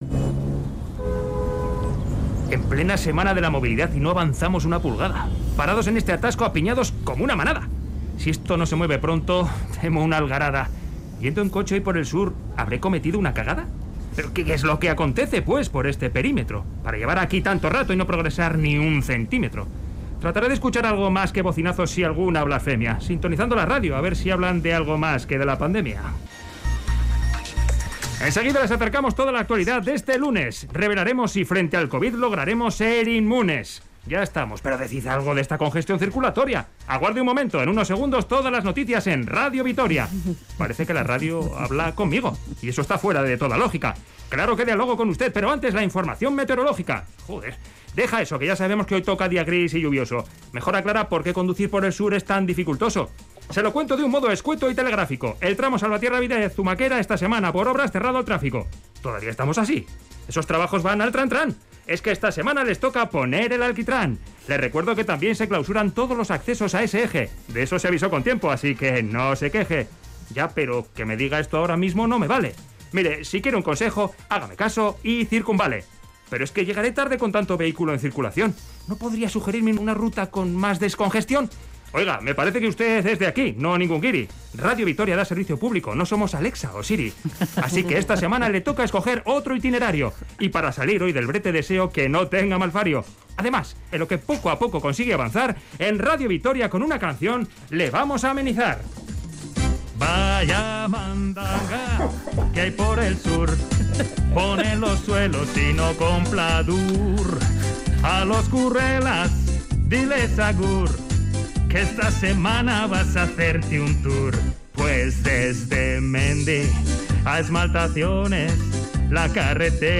Crónica en verso: Cerramos una semana por asfaltado